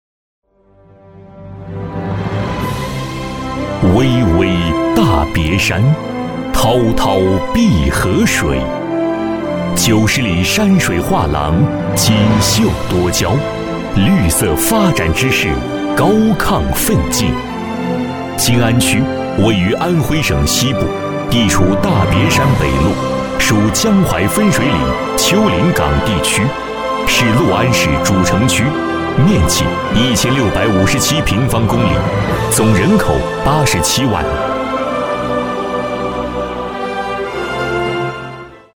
男国318_专题_政府_六安市区宣传_大气.mp3